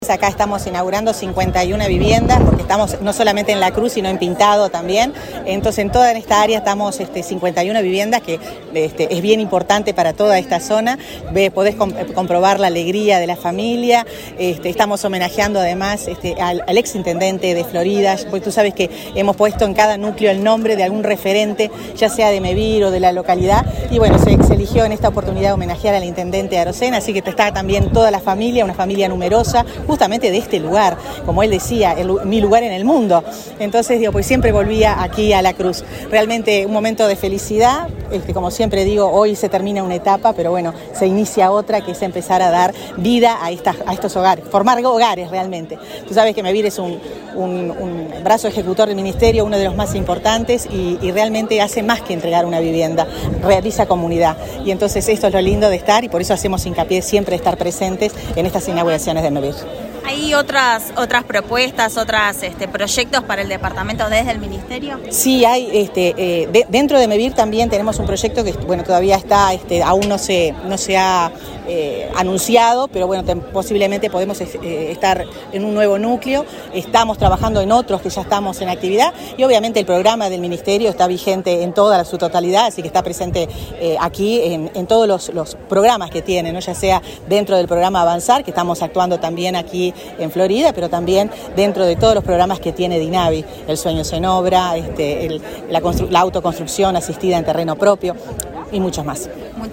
Declaraciones de la ministra de Vivienda, Irene Moreira
Este martes 15, la ministra de Vivienda, Irene Moreira, dialogó con Comunicación Presidencial, antes de participar en la inauguración de 51 viviendas